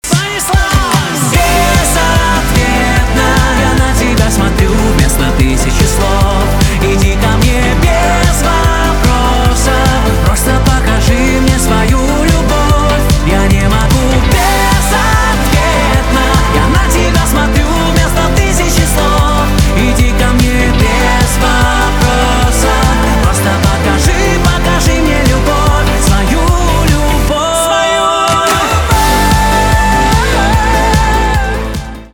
поп
романтические , чувственные , битовые